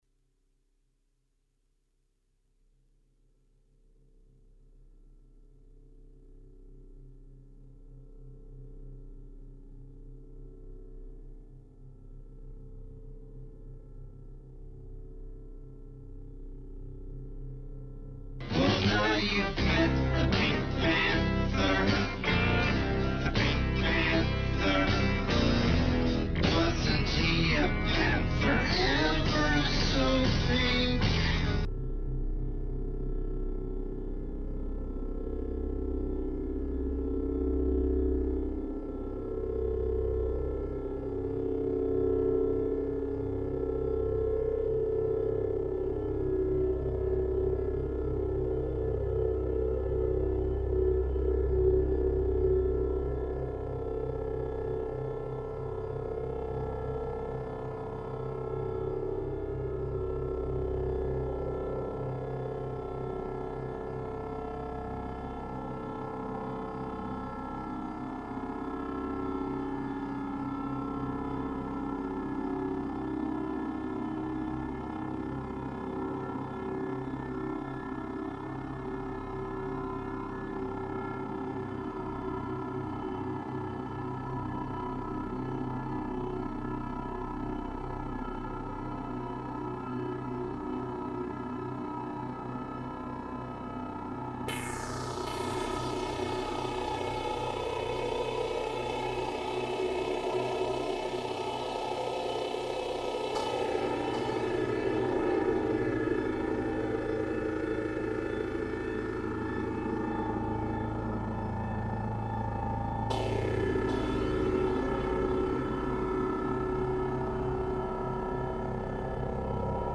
Very chill.
The samples are from two Serge panels.